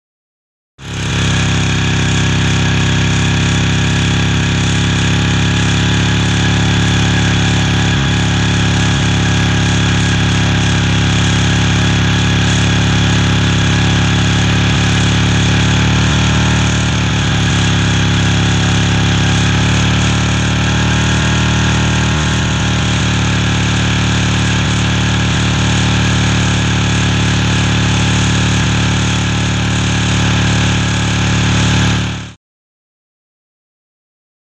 Motorcycle; Revs; Auto Cycle Static Revs As Per Riding On.